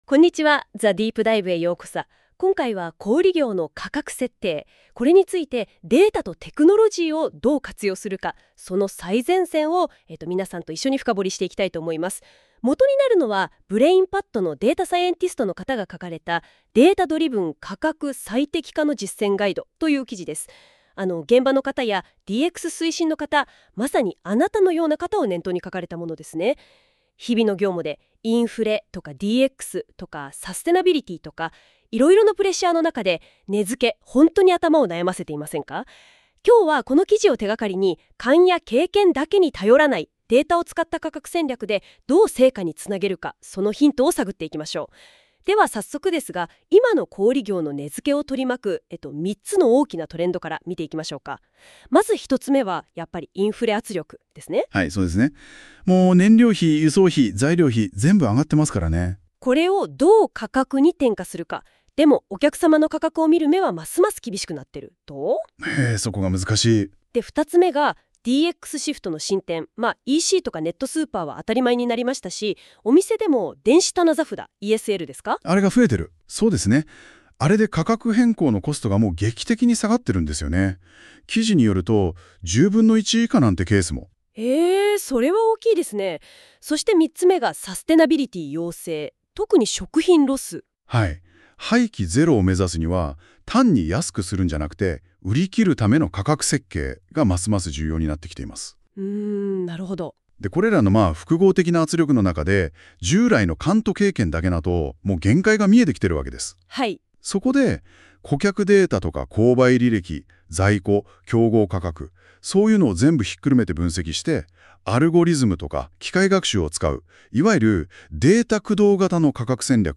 本記事の内容は、GoogleのNotebookLMを利用して作成した音声版でもお聞きいただけます。 生成AIによって自動生成された音声のため、多少の違和感や表現のゆらぎがありますが、通勤中などの「ながら聞き」にぜひご活用ください。